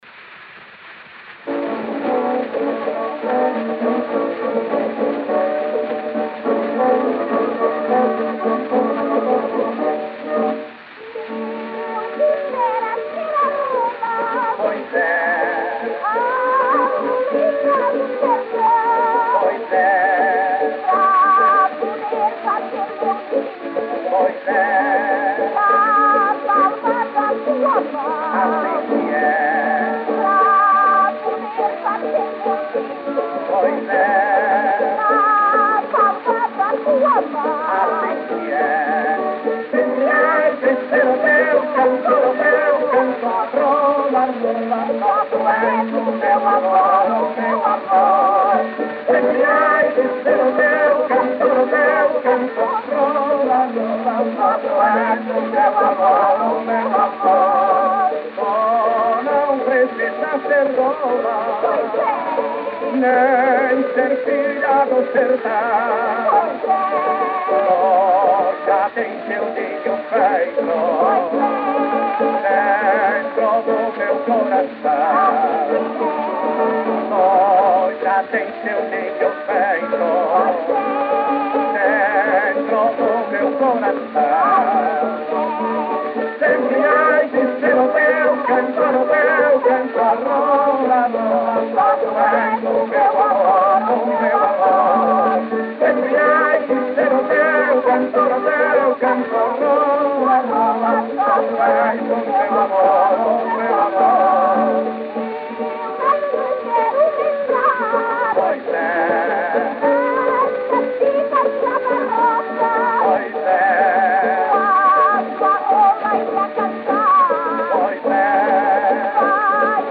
Disco de 78 rotações, também chamado "78 rpm", gravado nos dois lados e com rótulo "tricolor".
Gênero: Desafio nortista.